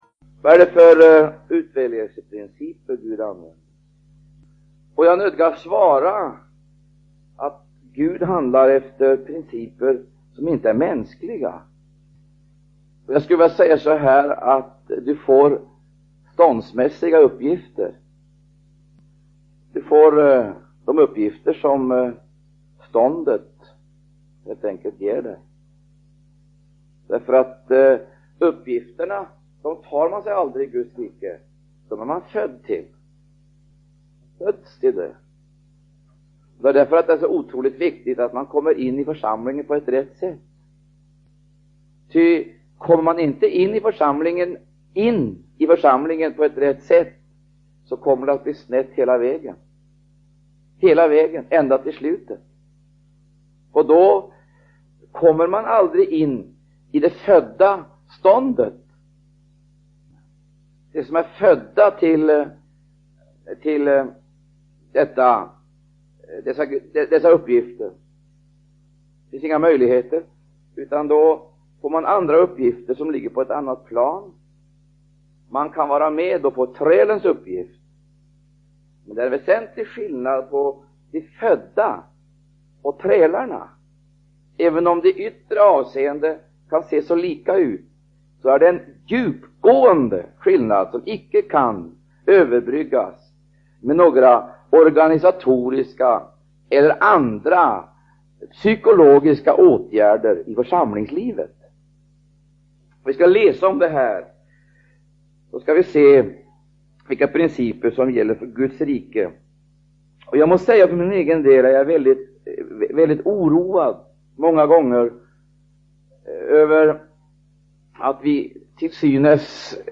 Undervisning